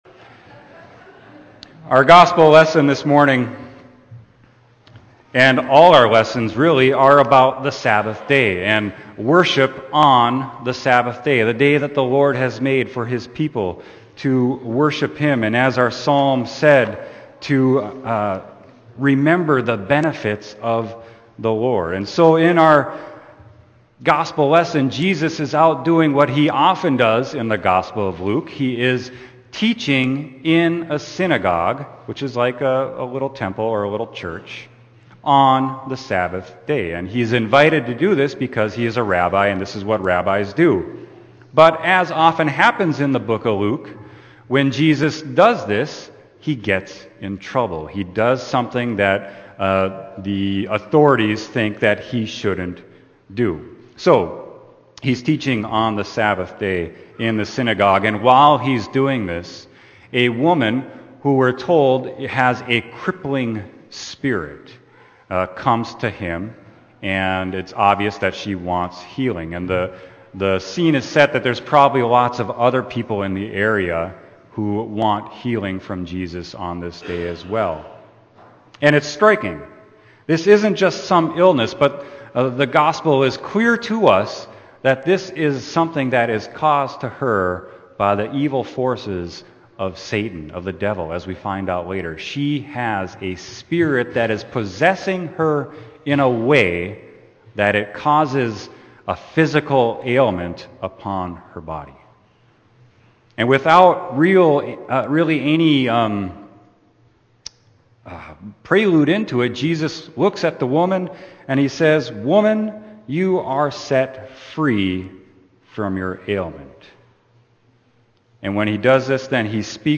Sermon: Luke 13.10-17